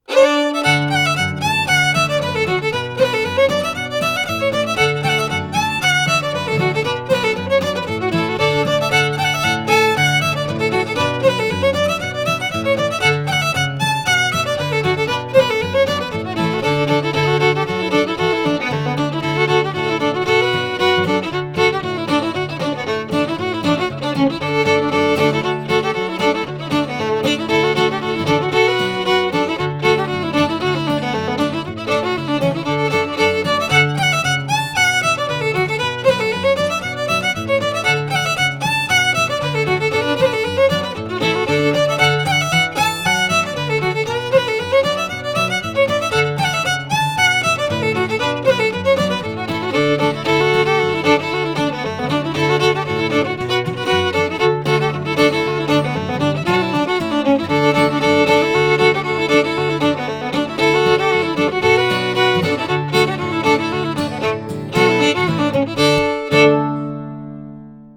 (112bpm)